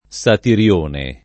[ S atir L1 ne ]